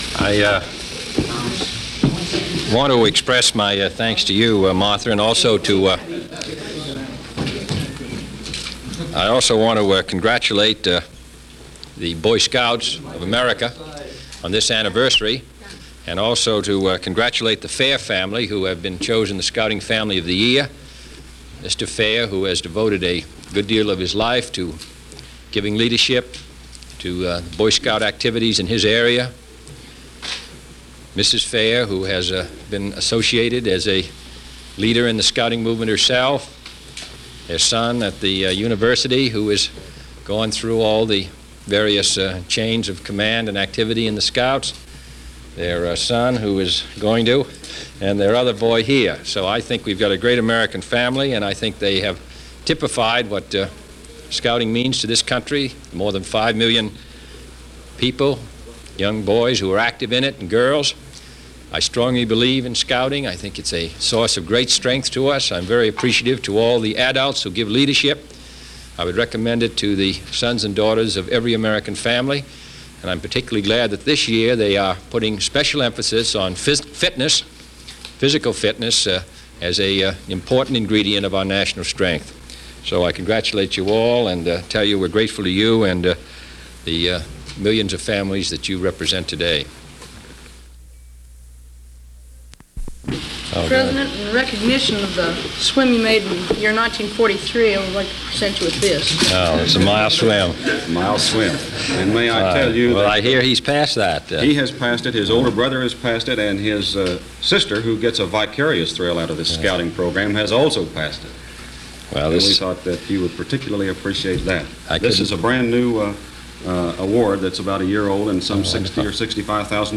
President Kennedy, saluting the representative Scout family, chosen by The Boy Scouts Of America for Scout Week on February 8, 1962.
Here is what former Boy Scout John F. Kennedy had to say about the tradition on February 8, 1962 from the Oval Office at The White House.